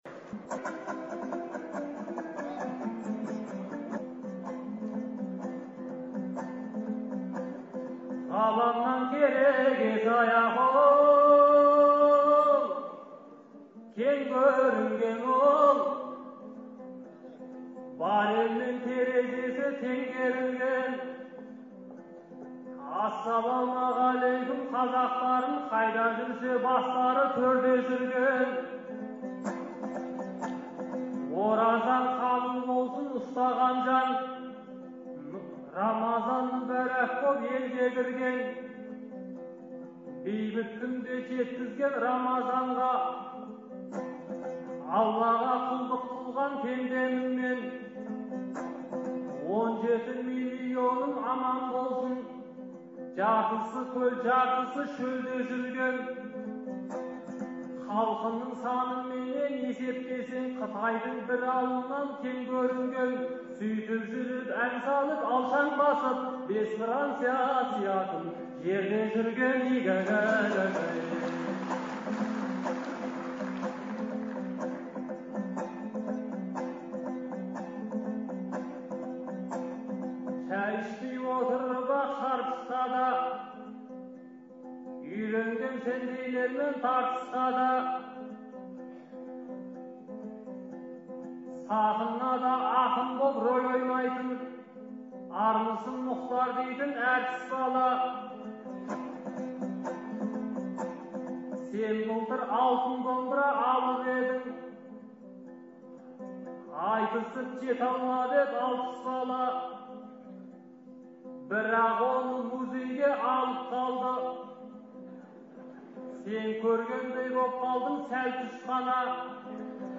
Шілденің 8-9-ы күндері Астанадағы «Қазақстан» орталық концерт залында «Ел, Елбасы, Астана» деген атпен ақындар айтысы өтті. Алғашқы күні айтысқан 20 ақынның арасынан іріктеліп шыққан он адам екінші күні бес жұп болды.